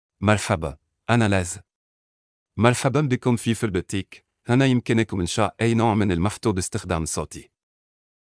Laith — Male Arabic (Syria) AI Voice | TTS, Voice Cloning & Video | Verbatik AI
MaleArabic (Syria)
Voice sample
Male
Laith delivers clear pronunciation with authentic Syria Arabic intonation, making your content sound professionally produced.